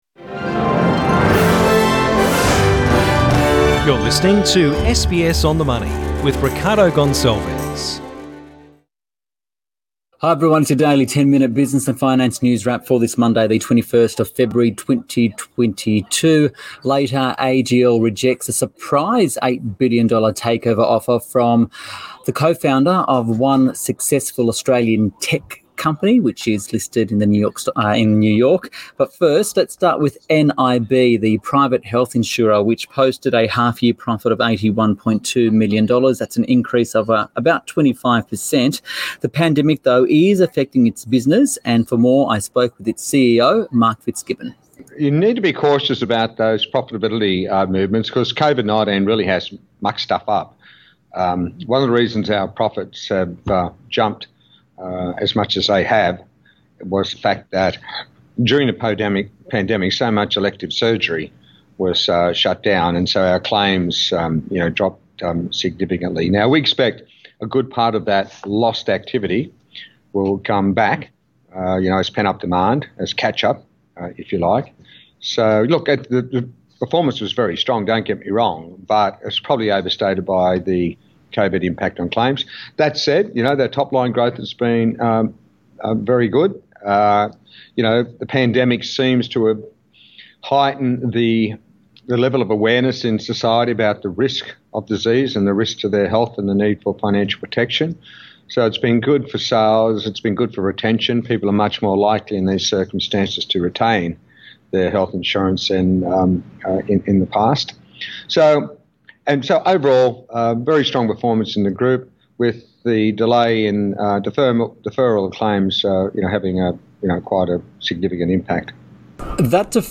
SBS On the Money: CEO Interview